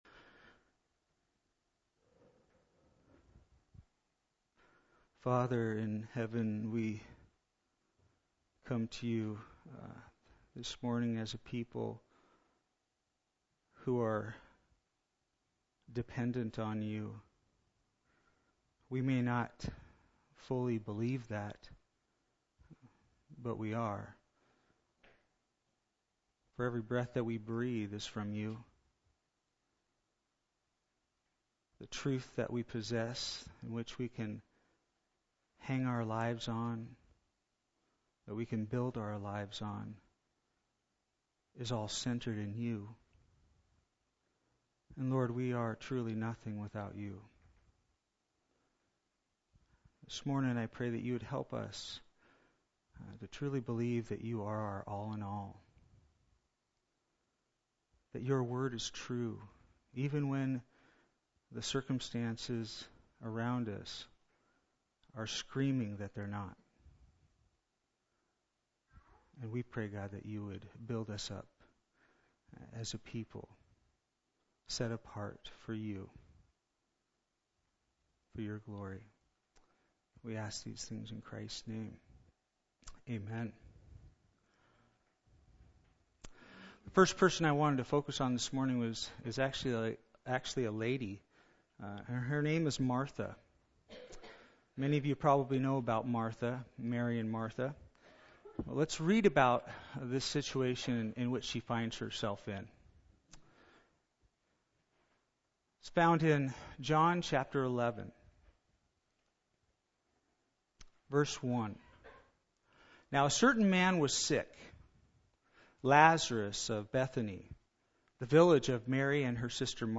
For Martha and Mary, the final exam was a lot harder than they ever expected (today’s sermon).